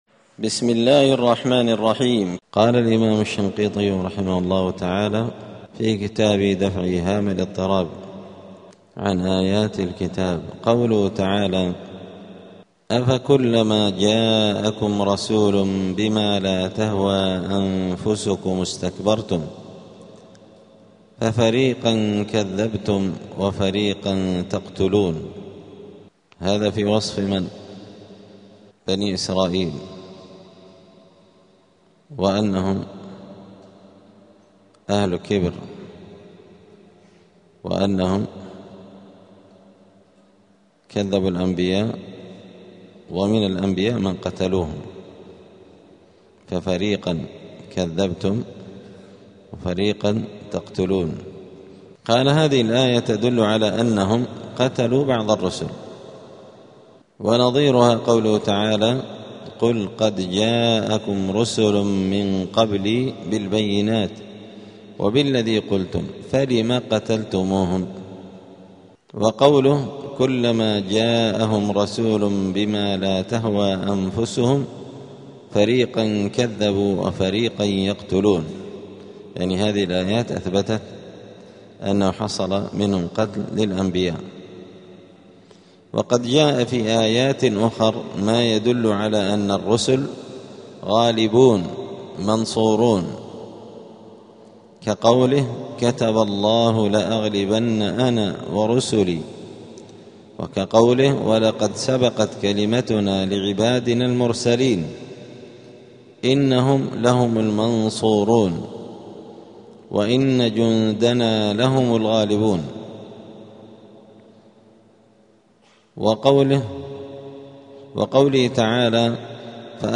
*الدرس الثامن (8) {سورة البقرة}.*